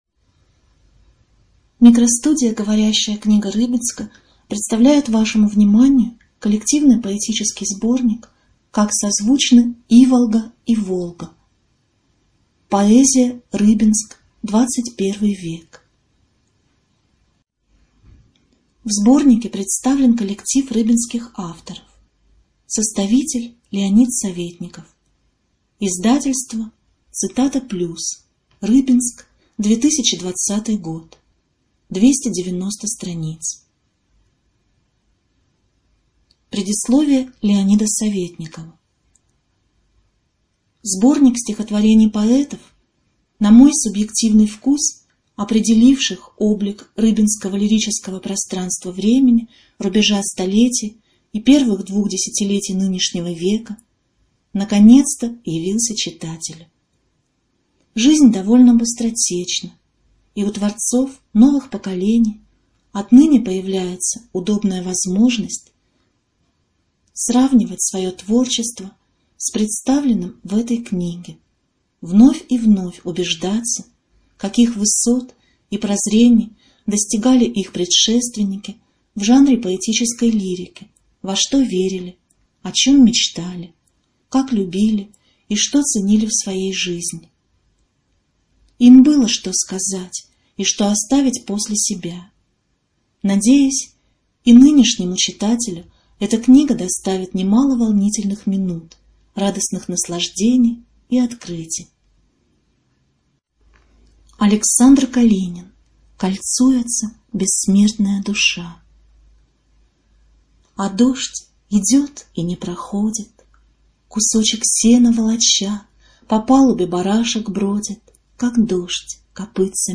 Студия звукозаписиГоворящая книга Рыбинска